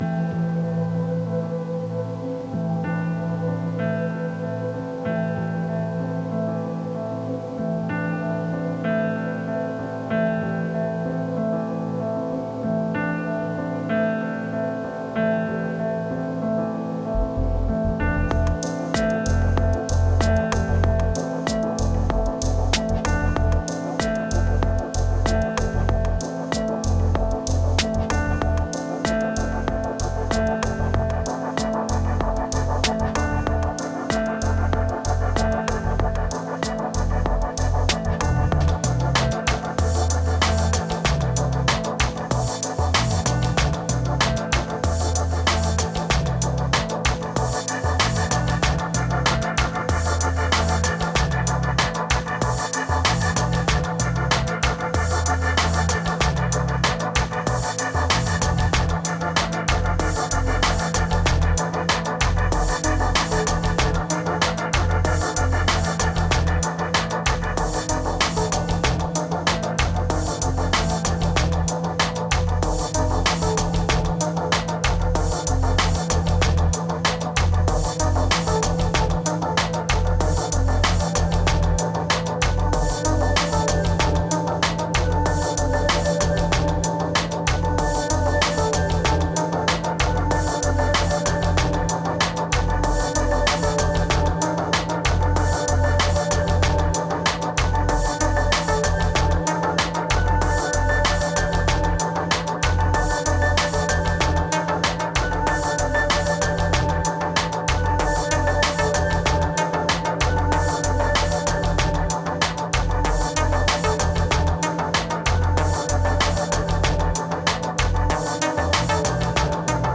Bad moods.
137📈 - 98%🤔 - 95BPM🔊 - 2026-01-24📅 - 623🌟